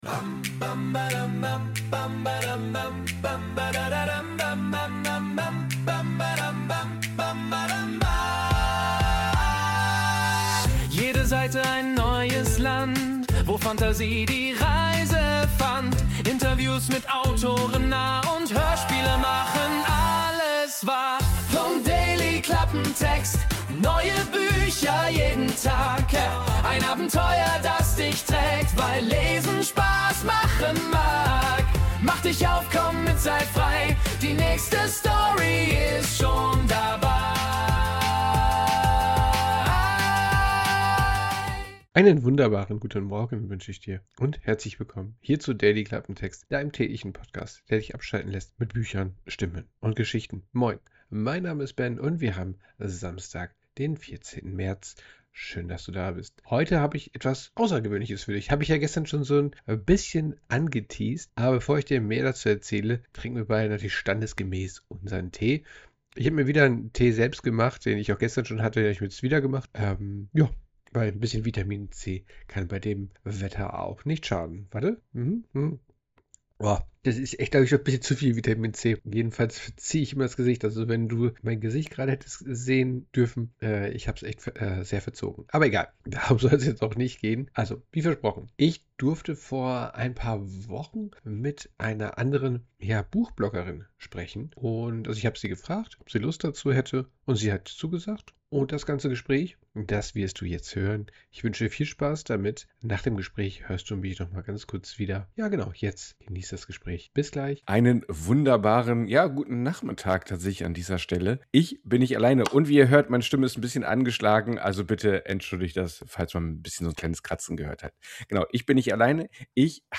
Ein schönes Gespräch zwischen Mikrofon und Bücherregal.